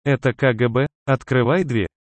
На этой странице собраны звуки, связанные с работой ФБР: перехваты переговоров, сигналы спецоборудования, тревожные гудки и другие эффекты.